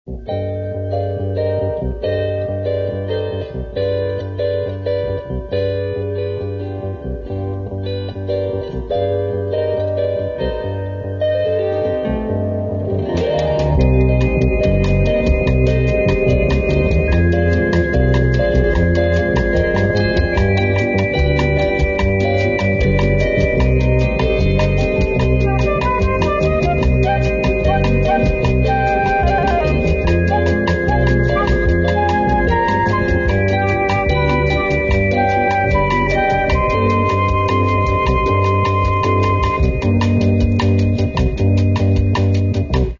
музыка: народна